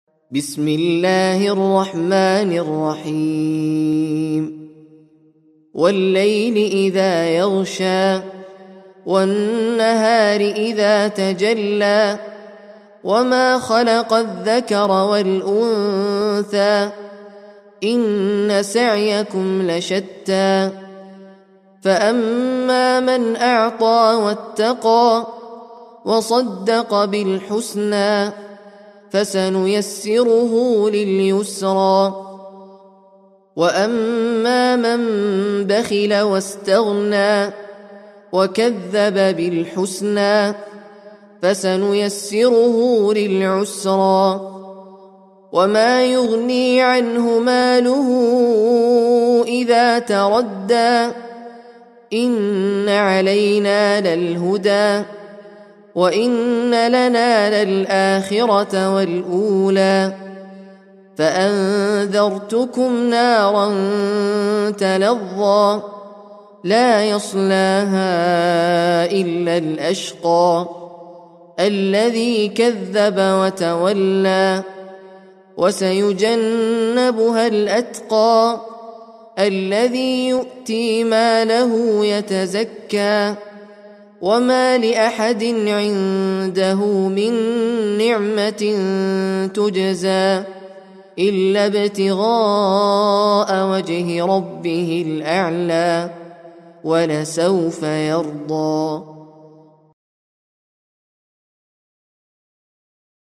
Sûrat Al-Layl (The Night) - Al-Mus'haf Al-Murattal